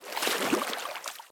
sound / walking / water-07.ogg
water-07.ogg